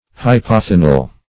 Search Result for " hypothenal" : The Collaborative International Dictionary of English v.0.48: Hypothenal \Hy*poth"e*nal\, Hypothenar \Hy*poth"e*nar\, a. [Pref. hypo- + thenar.]